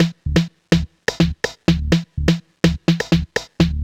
cch_jack_percussion_loop_fixed_125.wav